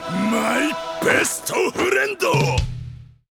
jujutsu kaisen besto friendo Meme Sound Effect